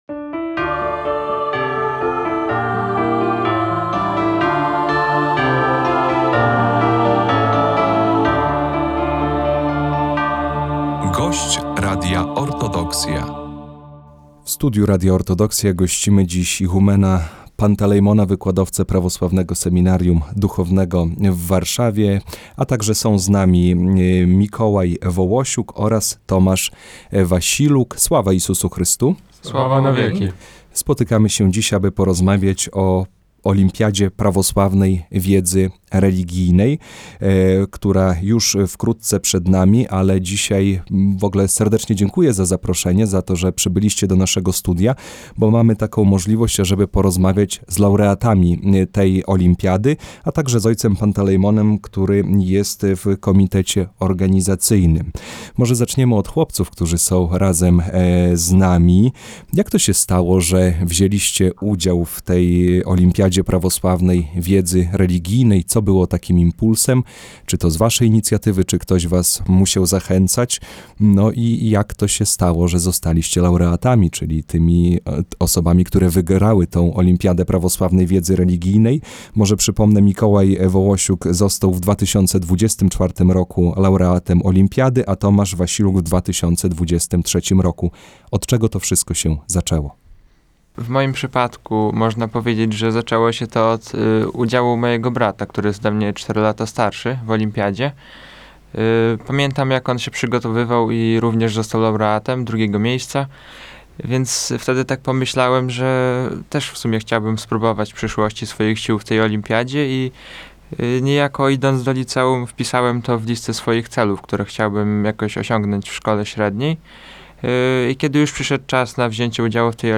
O tegorocznej olimpiadzie i o wspomnieniach z dwóch ostatnich edycji rozmawialiśmy